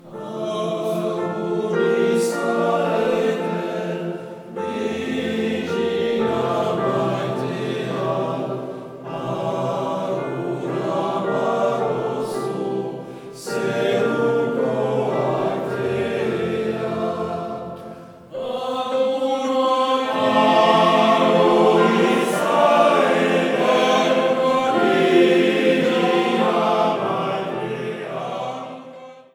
Un chœur d’hommes
Selon les œuvres, le choeur chante a cappella ou avec accompagnement instrumental et se produit parfois en partenariat avec d’autres ensembles.